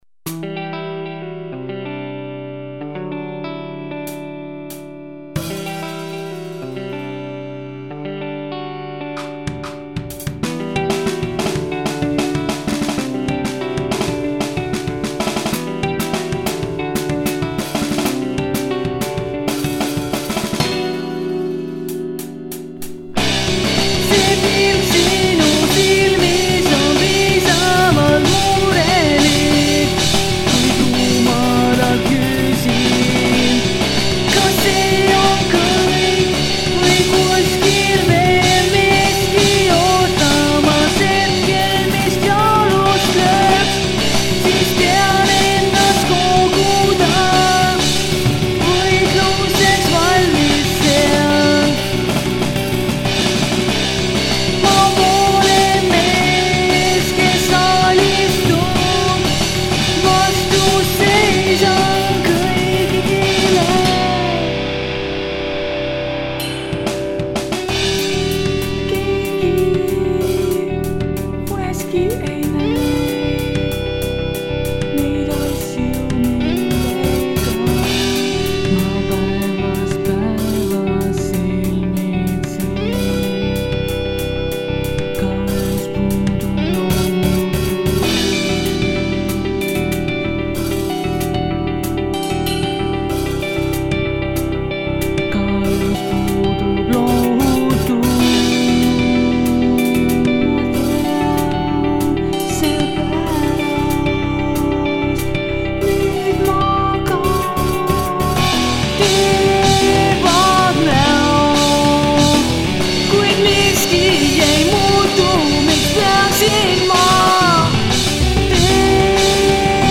Some time ago I made a quick recording of a song I wanted to play with my new band and to give them some ideas.
link .. music is done in Guitar Pro. I hope my high pitched singing voice doesn't scare you :D
Most people find it to be quite unique.